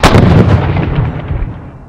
plane_preexp1.ogg